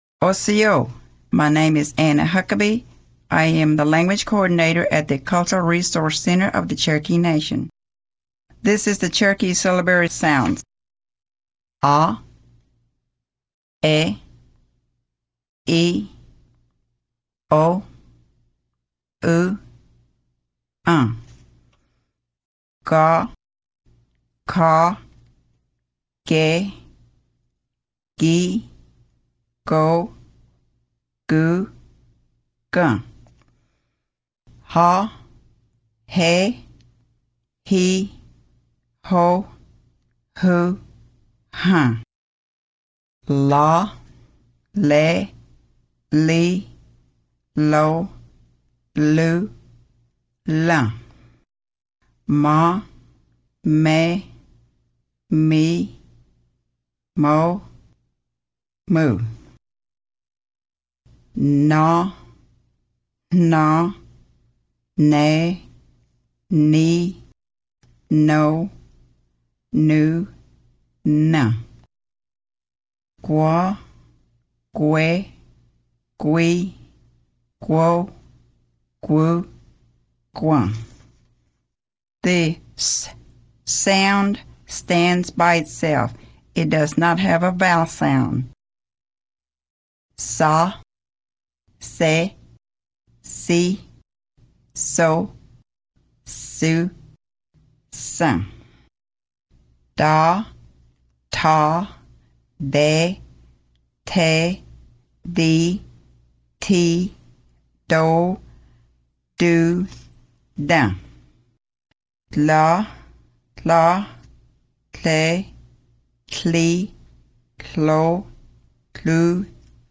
Cherokee Audio Lessons